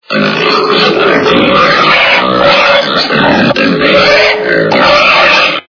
Звук кабана - Хрюканя кабана Звук Звуки Звук кабана - Хрюканя кабана
» Звуки » Природа животные » Звук кабана - Хрюканя кабана
При прослушивании Звук кабана - Хрюканя кабана качество понижено и присутствуют гудки.